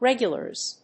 /ˈrɛgjʌlɝz(米国英語), ˈregjʌlɜ:z(英国英語)/